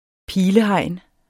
pilehegn substantiv, intetkøn Bøjning -et, -, -ene Udtale [ ˈpiːlə- ] Betydninger 1. hegn bestående af flettede pilekviste eller -grene I begyndelsen var pilehegnet grønt, fordi pilegrenene var friske.